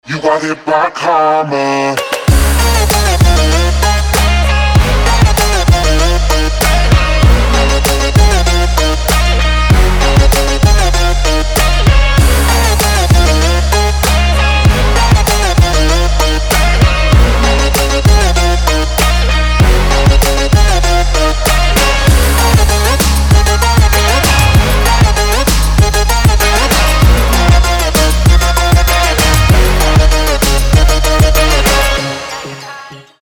• Качество: 320, Stereo
Electronic
Красивый электронный саунд!